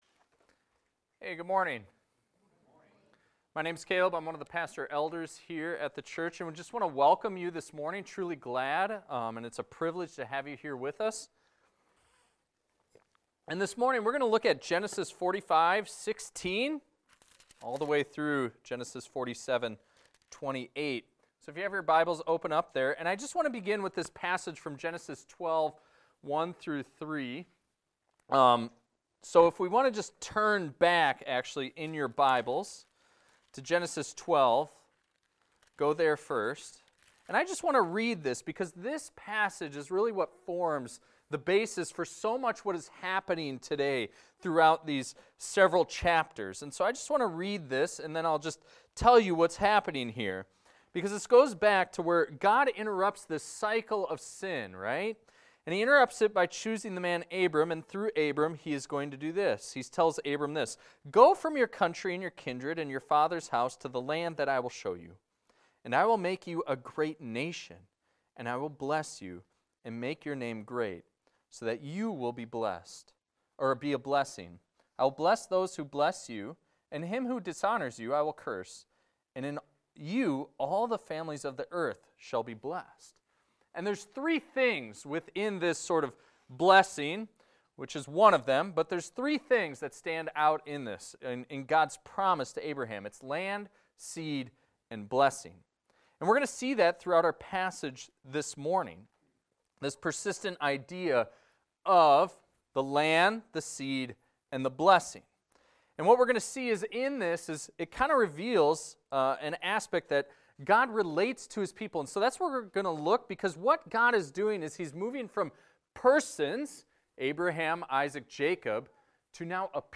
This is a recording of a sermon titled, "A People Set Apart."